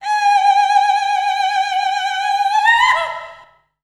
Index of /90_sSampleCDs/Voices_Of_Africa/VariousPhrases&Chants
21_Undulating.WAV